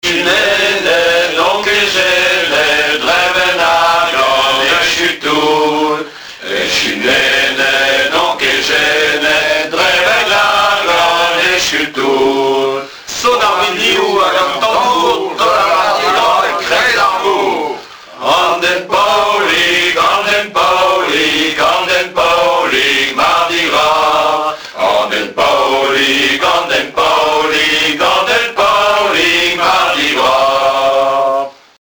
Mardi-Gras
Témoignages et chansons
Pièce musicale inédite